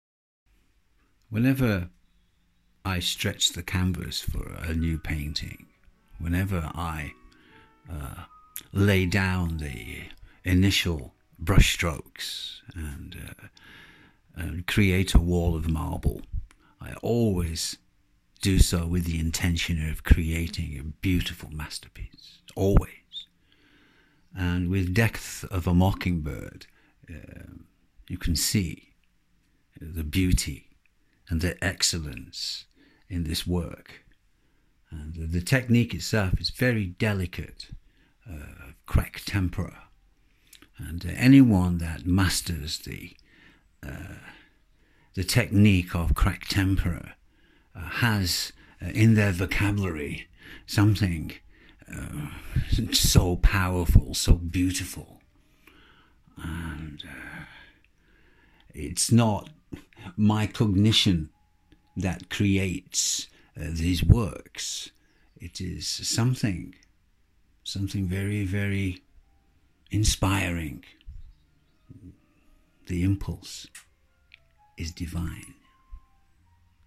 talking